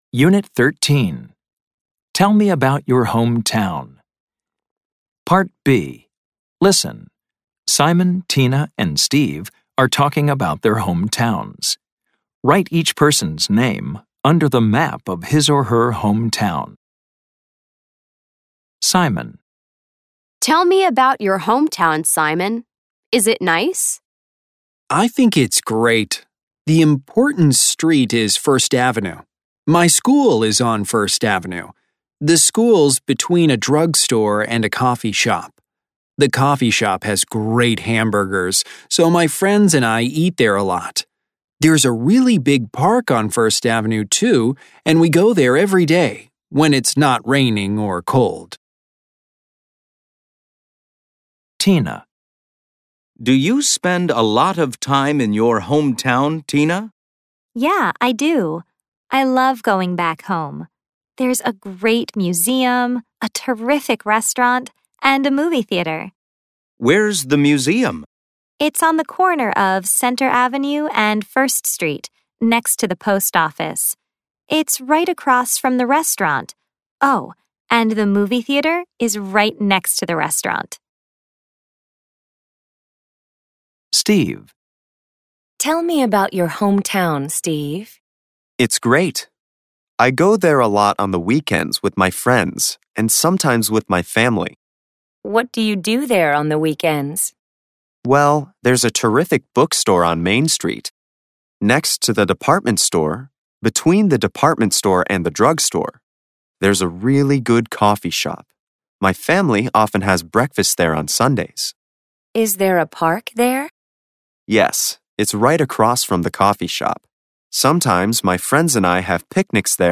American English
This includes Snapshots, Conversations, Grammar Focus, Listening, Pronunciation practice, Word Power, and Reading, all recorded in natural conversational English.